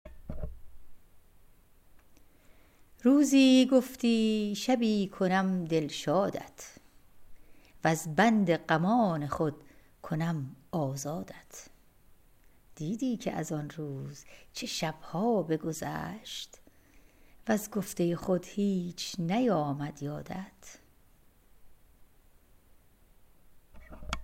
Poem recited